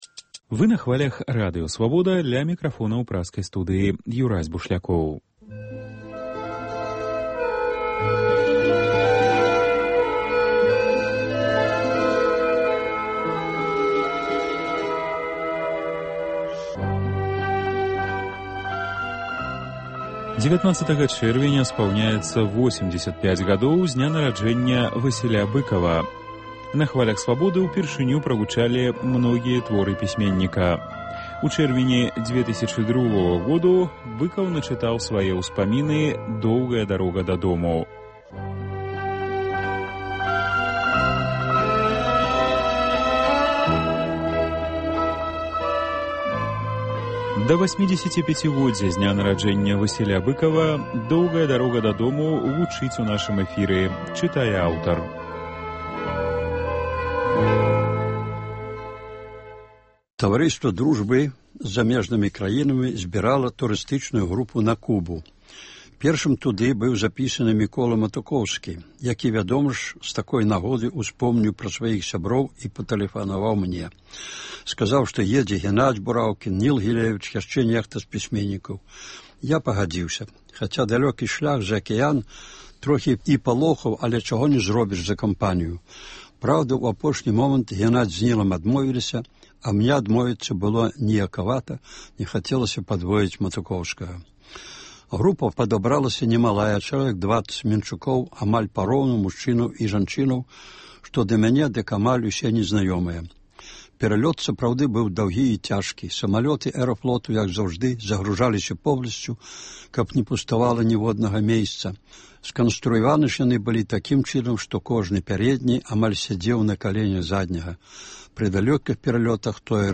Сёлета ў чэрвені штодня ў нашым эфіры гучыць “Доўгая дарога дадому” ў аўтарскім чытаньні. Сёньня – частка 9-ая.